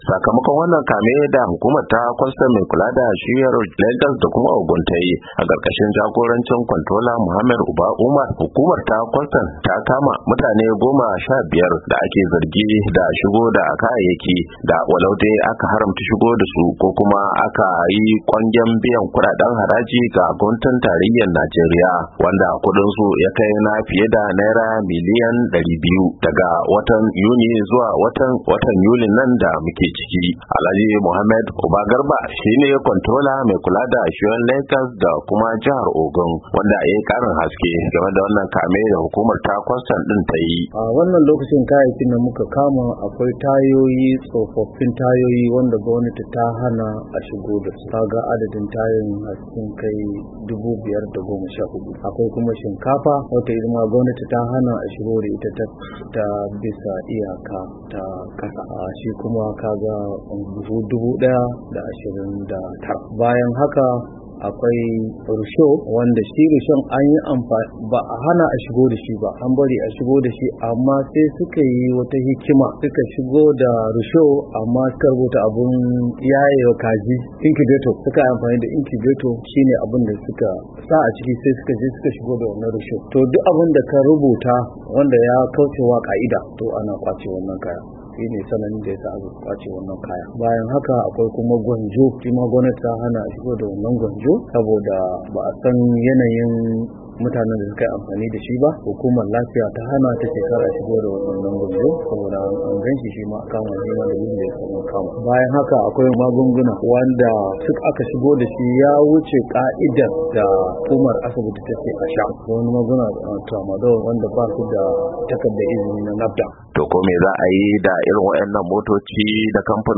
WASHINGTON D.C. —